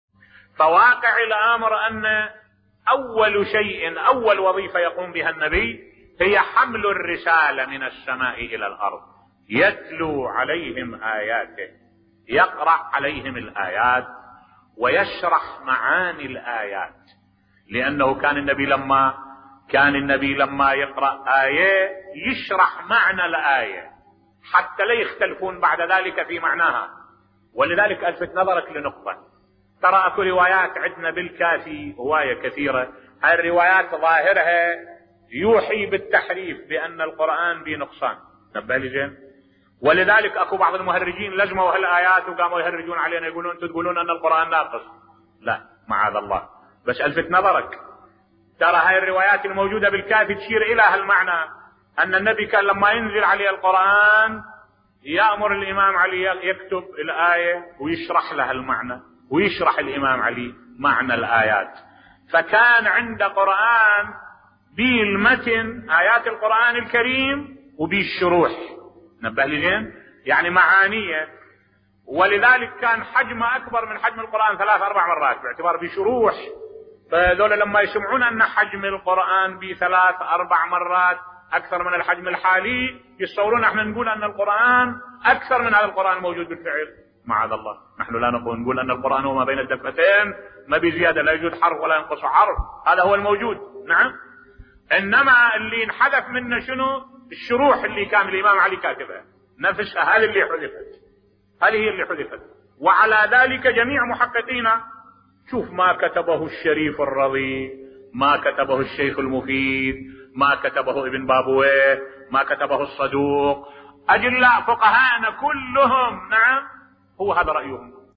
ملف صوتی سبب اشتباههم أننا نحرف القرآن الكريم بصوت الشيخ الدكتور أحمد الوائلي